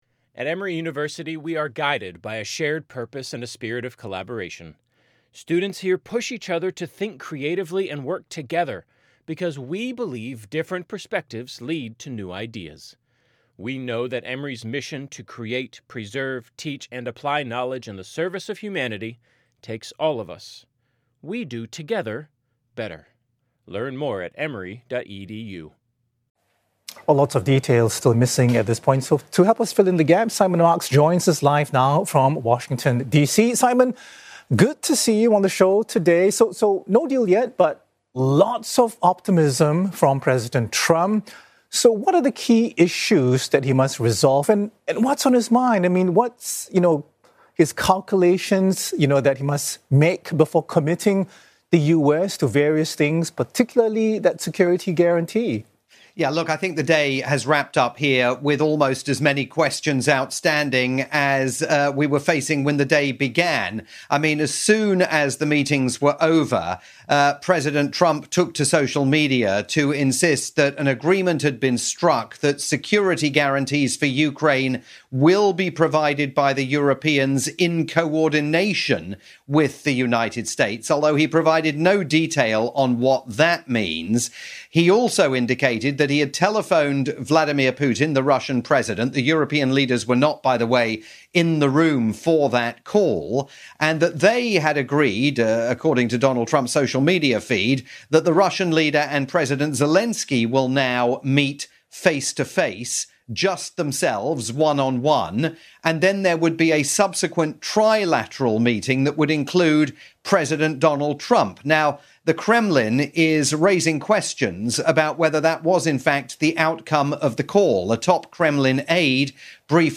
live update on the culmination of today's day-long events at the White House involving President Volodymyr Zelensky of Ukraine and 7 European leaders.